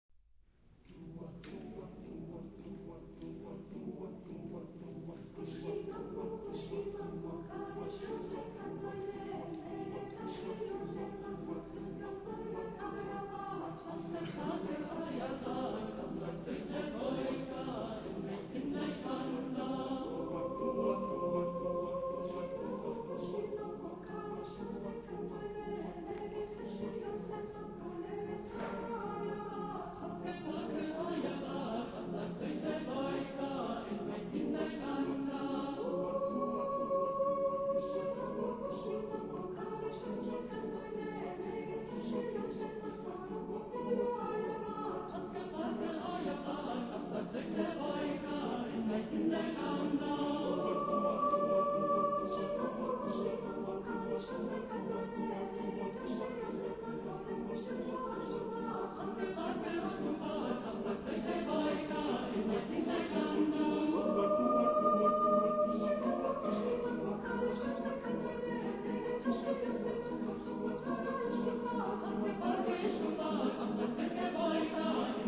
Genre-Style-Form: Folk music ; Contemporary
Type of Choir: SATBB  (5 mixed voices )
Musicological Sources: Vepsian folk tune